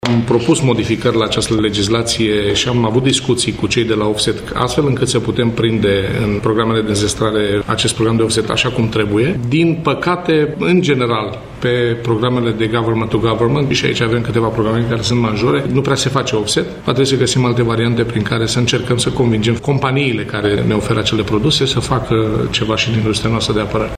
Bugetul Apărării va fi şi anul acesta de 2 la sută din Produsul Intern Brut, iar minimum 20 de procente vor fi folosite pentru înzestrare susţine ministrul Apărării Naţionale, Gabriel Leş, într-un interviu pentru Radio România. El afirmă că este nevoie de îmbunătăţirea legislaţiei, astfel încât o parte din banii cheltuiţi să fie investiţi în producţia militară din România.